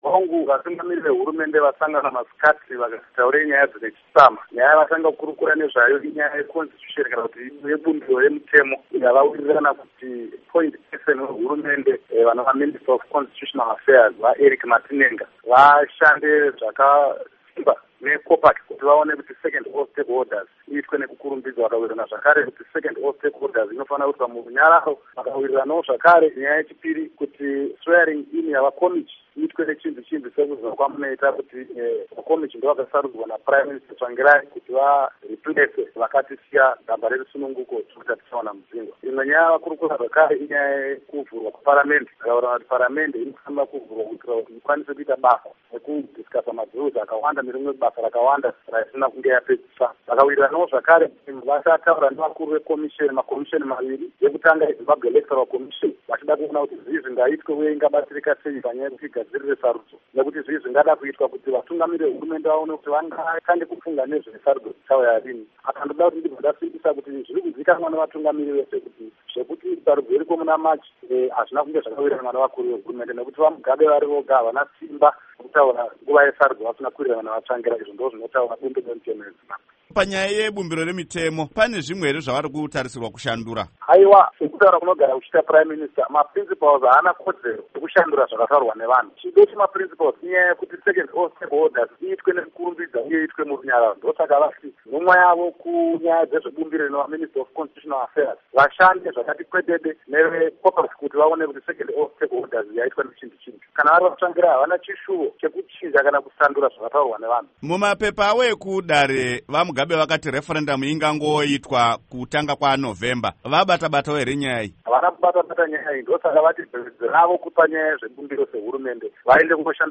Hukurukuro